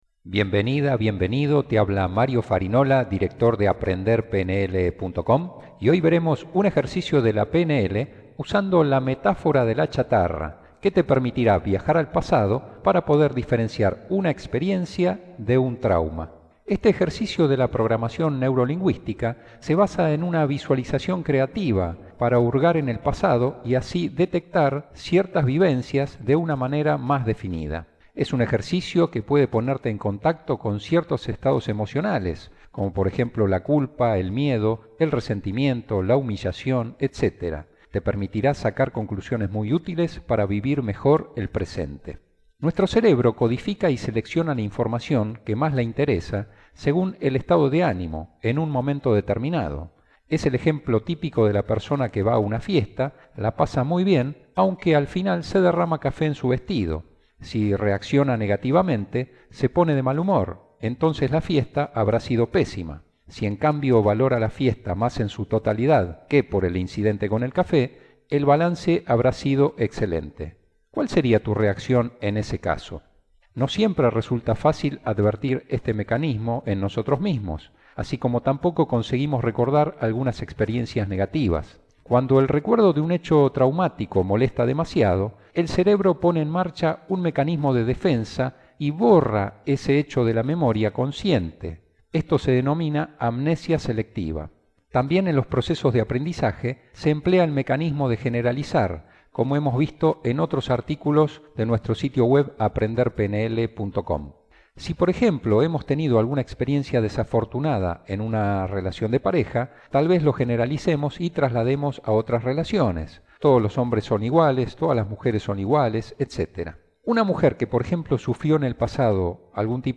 Hoy te comparto Audio mp3 con un Ejercicio de la PNL, que básicamente es una visualización creativa, un “viaje” hacia tu pasado… cuando tenías aproximadamente 5 años.